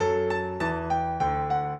piano
minuet13-11.wav